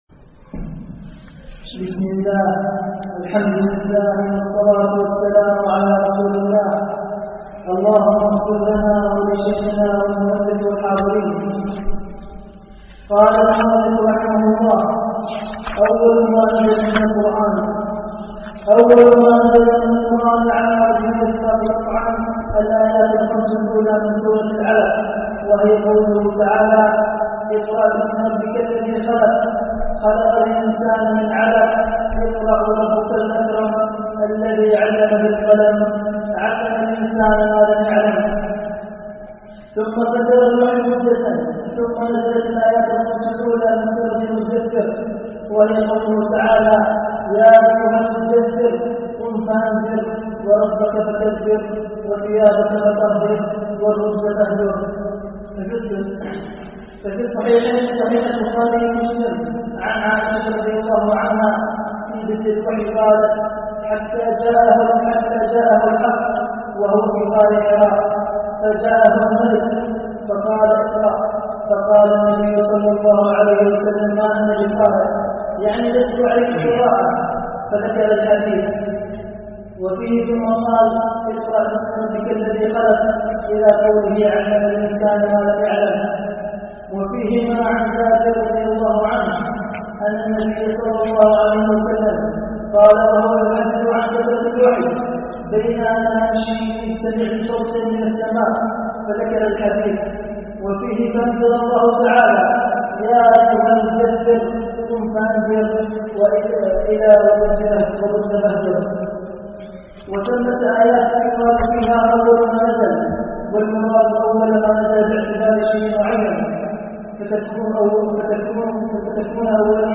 شرح أصول في التفسير ـ الدرس الثاني
الألبوم: دروس مسجد عائشة